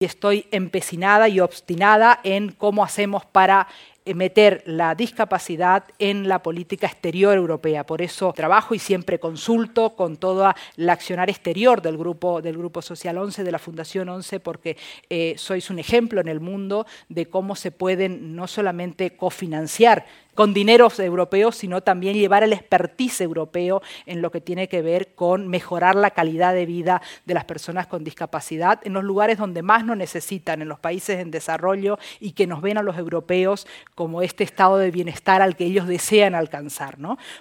En el mismo ha intervenido la eurodiputada por Madrid, Mónica Silvana, quien ha destacado la importancia de este proyecto ‘Europa se acerca a ti’ “para dar cuenta de nuestro trabajo y recoger las necesidades de la ciudadanía con discapacidad que deben ser tenidas en cuenta en los distintos fondos estructurales de la Unión Europea”.